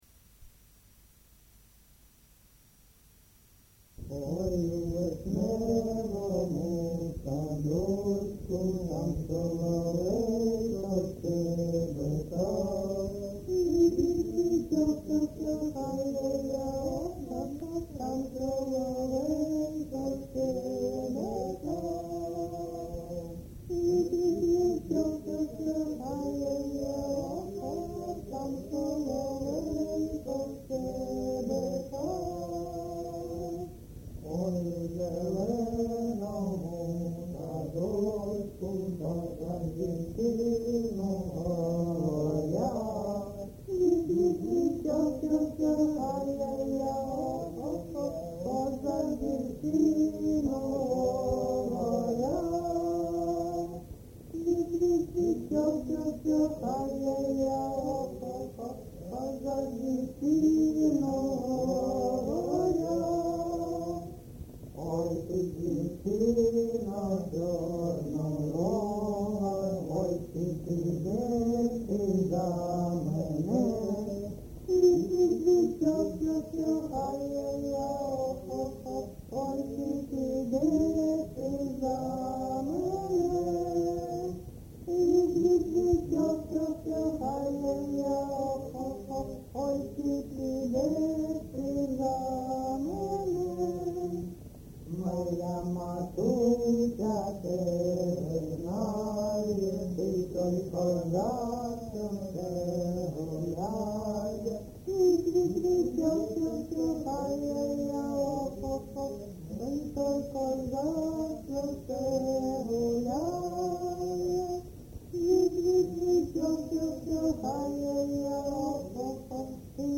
ЖанрПісні з особистого та родинного життя, Пісні літературного походження
Місце записум. Часів Яр, Артемівський (Бахмутський) район, Донецька обл., Україна, Слобожанщина